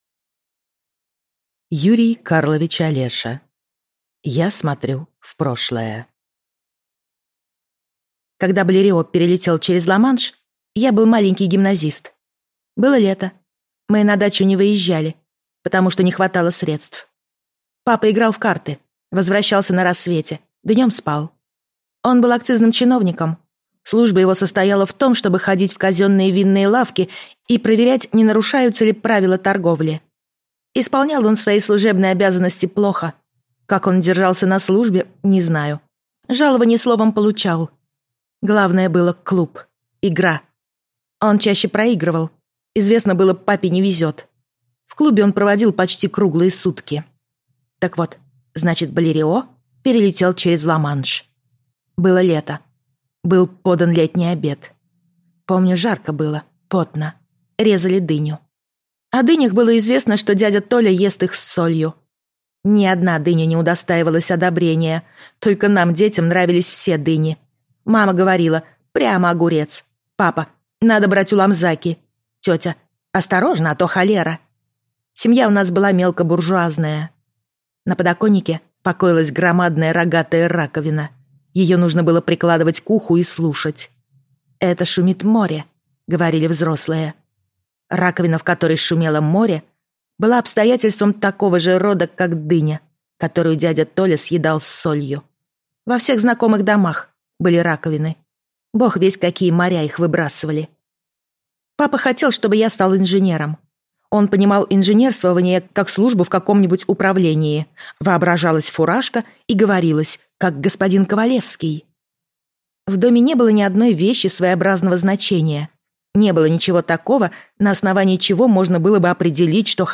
Аудиокнига Я смотрю в прошлое | Библиотека аудиокниг